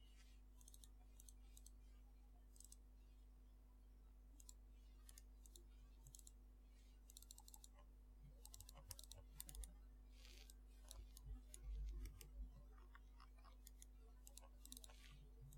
鼠标点击
描述：点击电脑鼠标。
标签： 点击 鼠标 电脑 点击
声道立体声